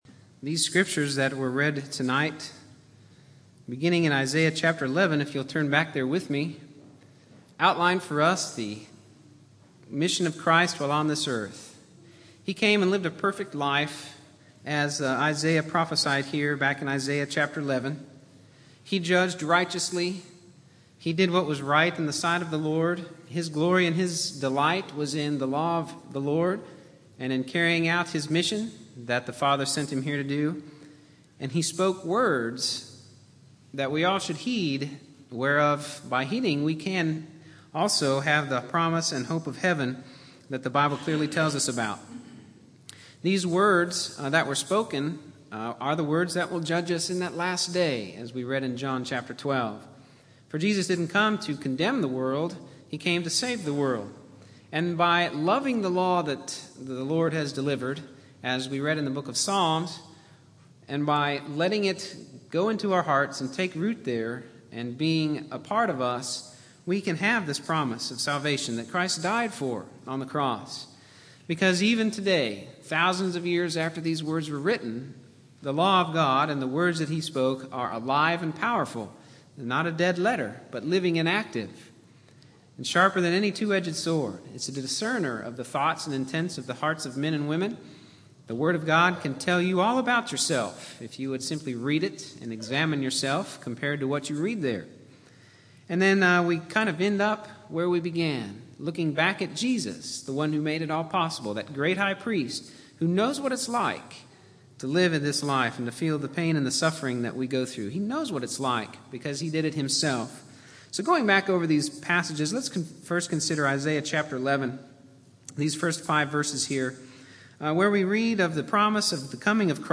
Categories: Sermons